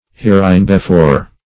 Search Result for " hereinbefore" : Wordnet 3.0 ADVERB (1) 1. in the preceding part of the current text ; The Collaborative International Dictionary of English v.0.48: Hereinbefore \Here`in*be*fore"\, adv. In the preceding part of this (writing, document, book, etc.).